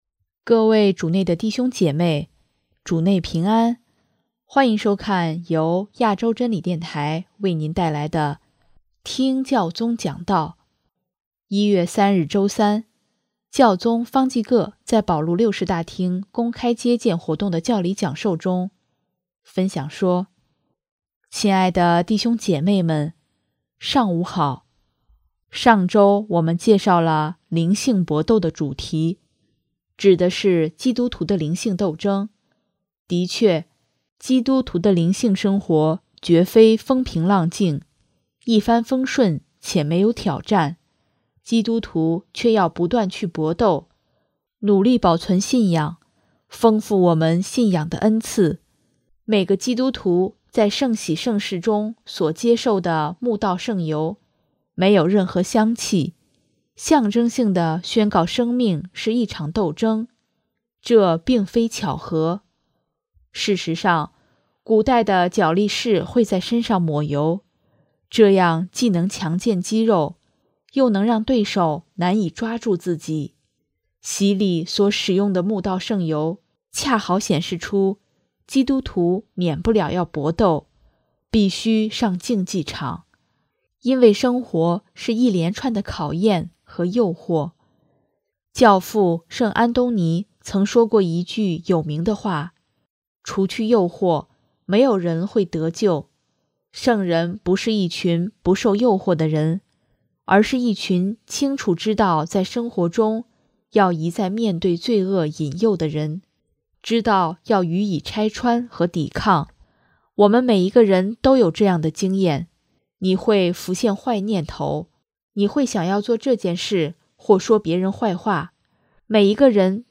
1月3日周三，教宗方济各在保禄六世大厅公开接见活动的教理讲授中，分享说：